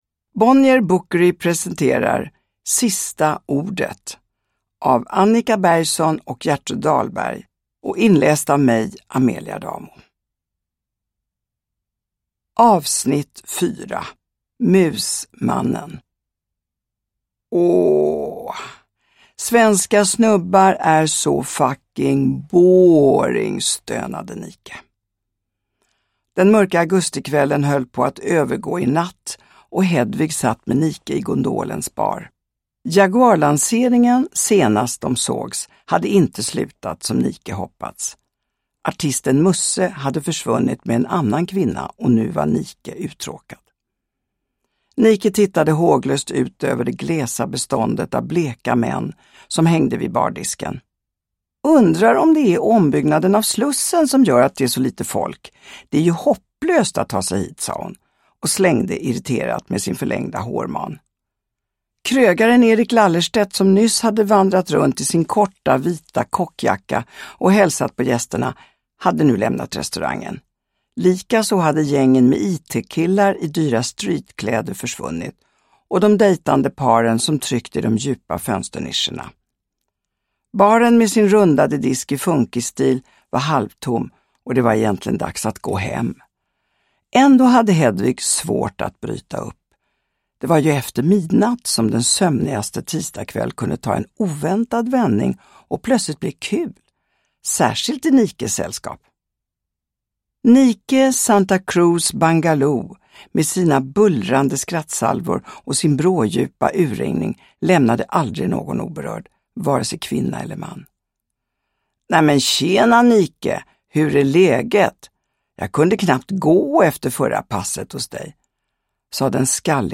Uppläsare: Amelia Adamo